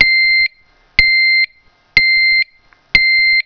LUZ FIJA DE LED - SONIDO CONTINUO
4 Módulos: Rojo/Ámbar/Verde/Transparente + Zumbador
698_K37 CONTINUO.wav